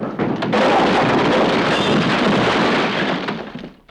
Index of /90_sSampleCDs/E-MU Producer Series Vol. 3 – Hollywood Sound Effects/Human & Animal/Falling Branches
WOOD DEBR03L.wav